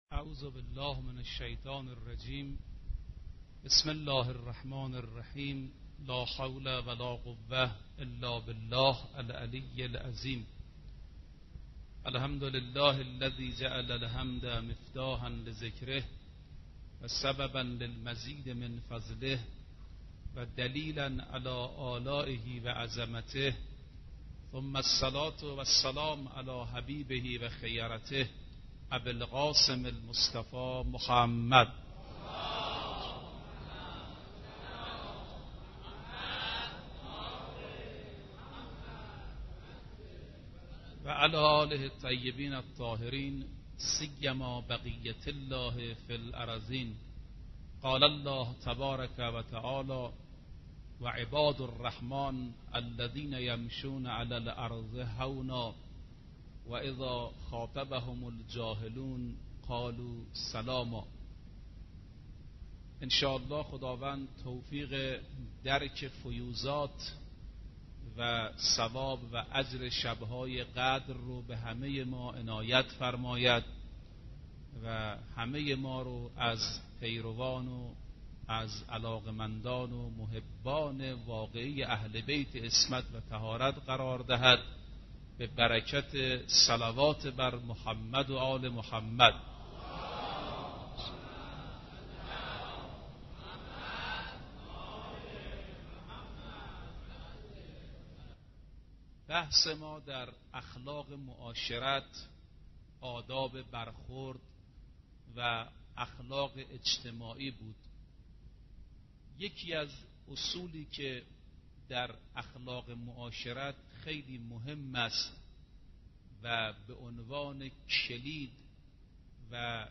آرشیو ماه مبارک رمضان - سخنرانی - بخش پانزدهم